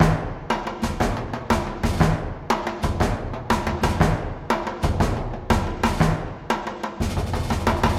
陷阱式低音鼓和小鼓
描述：用Kong鼓设计器Reason 8.3.2制作的低音鼓和小鼓
Tag: 137 bpm Trap Loops Drum Loops 2.36 MB wav Key : Unknown